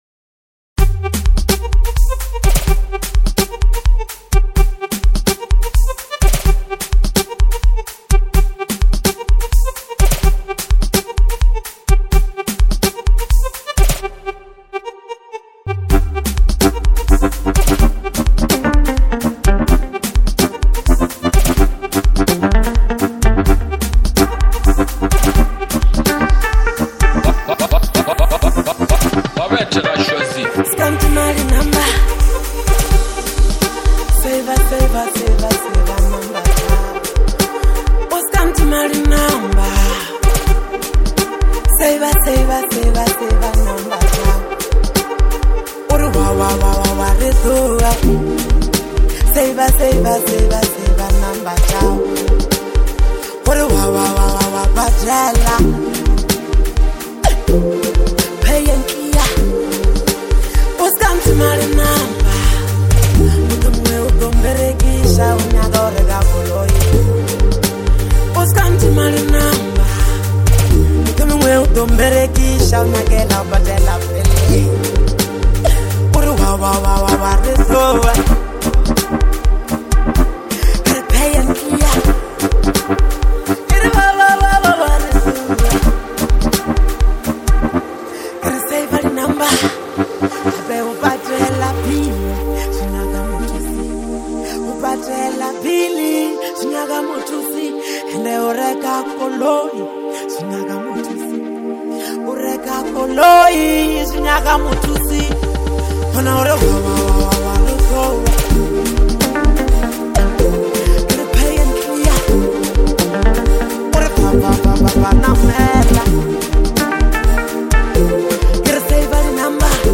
Lekompo
Genre: Lekompo.